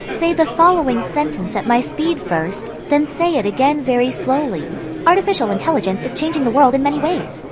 rhythm1_Babble_1.wav